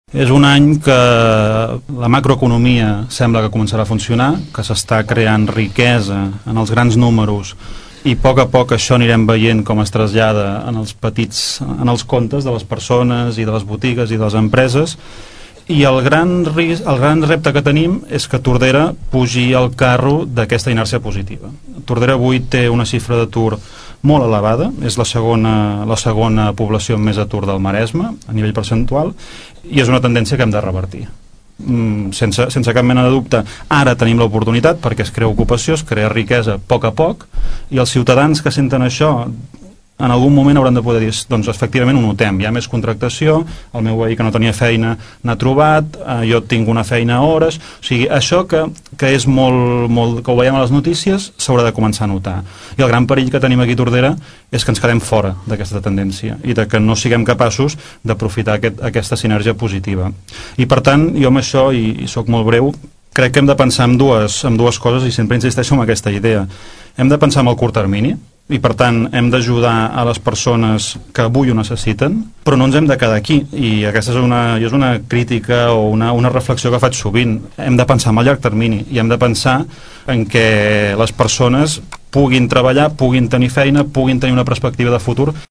La tertúlia de polítics se celebra cada mes, la següent setmana després del plenari municipal.